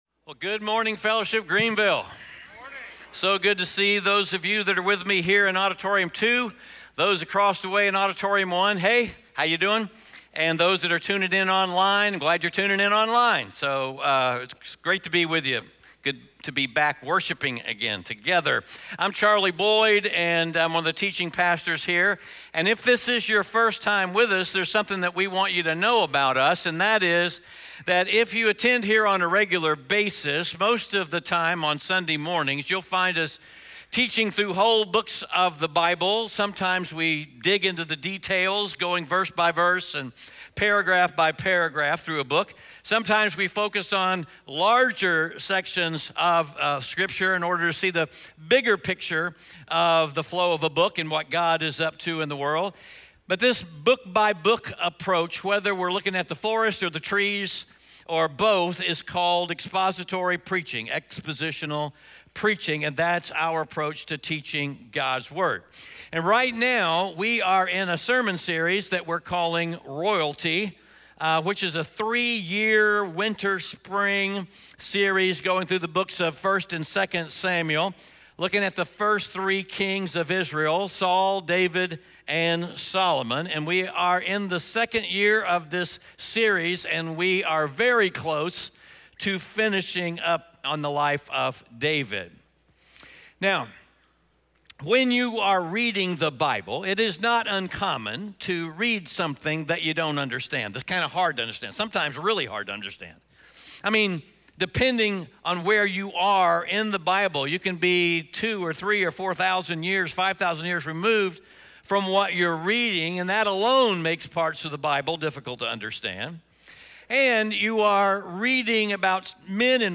2 Samuel 24 Audio Sermon Notes (PDF) Ask a Question Scripture: 2 Samuel 24 SERMON SUMMARY So, how do you end a book on the life of David, Israel’s greatest king and the king from whom King Jesus will come?